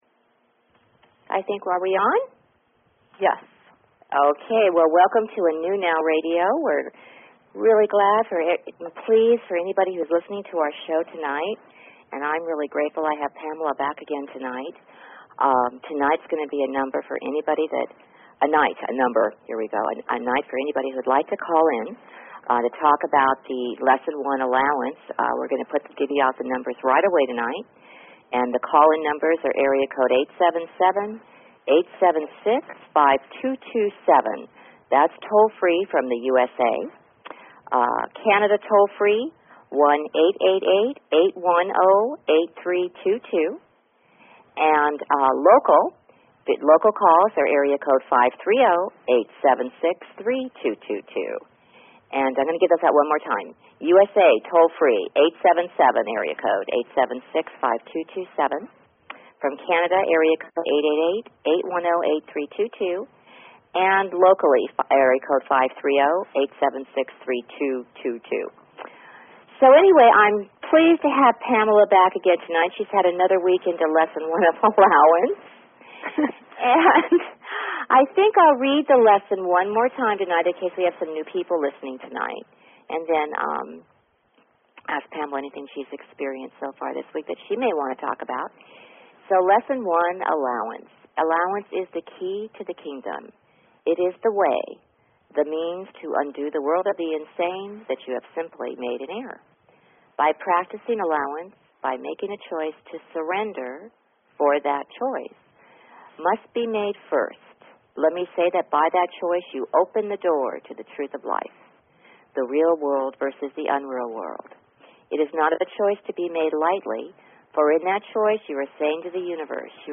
Talk Show Episode, Audio Podcast, The_New_Now and Courtesy of BBS Radio on , show guests , about , categorized as